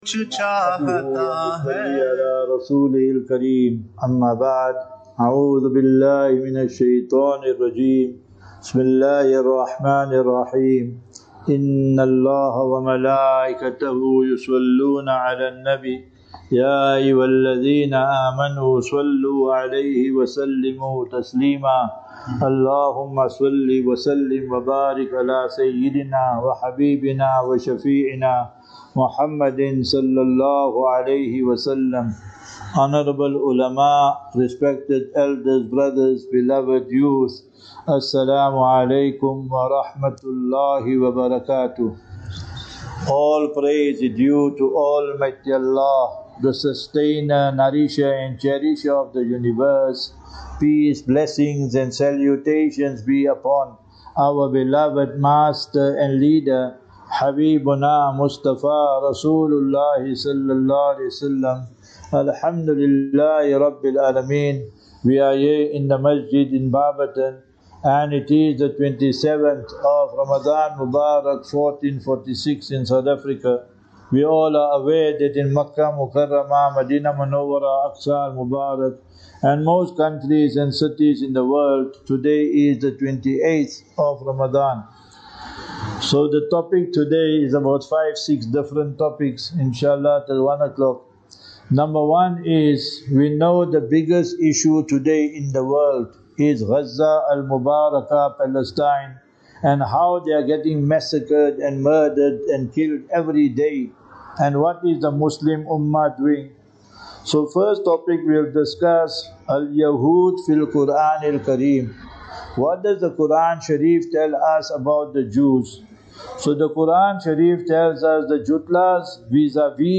28 Mar 28 March 25 - Ramadan 1446 - Jumu'ah Lecture at Barberton Masjid (Mpumalanga)